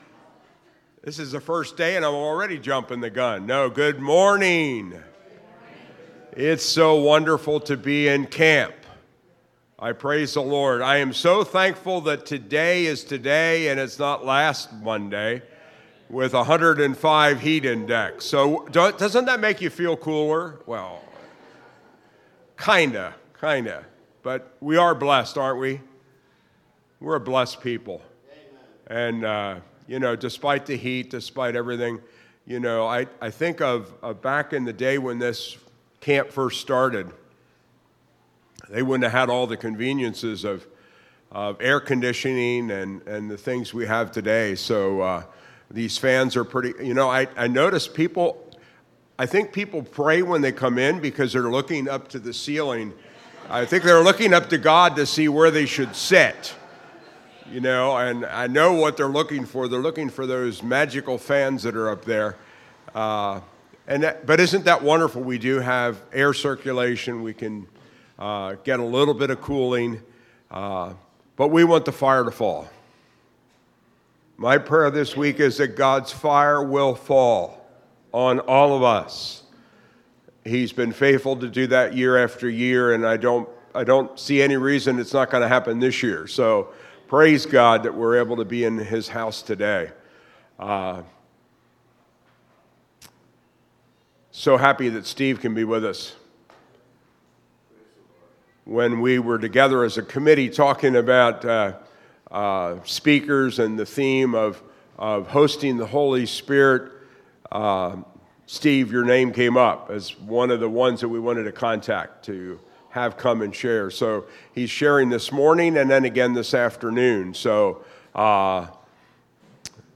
Monday Morning Campmeeting, June 30, 2025